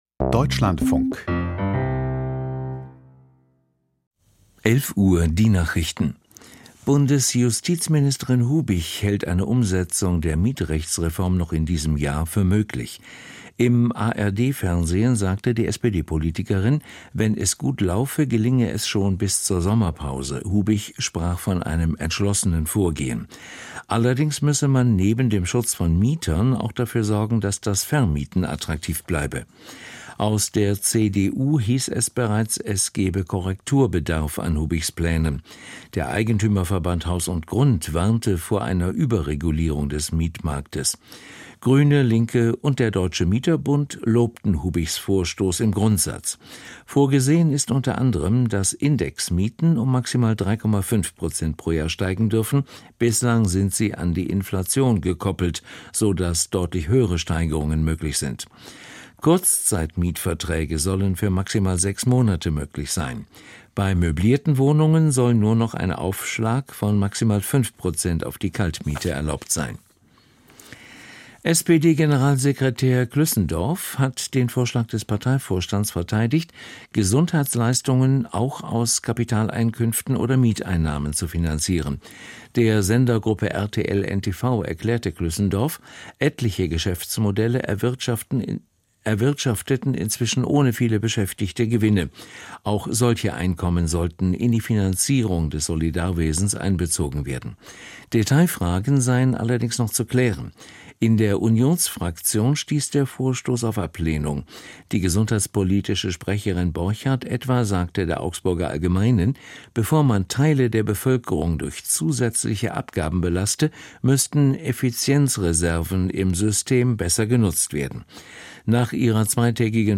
Die Nachrichten vom 09.02.2026, 11:00 Uhr
Aus der Deutschlandfunk-Nachrichtenredaktion.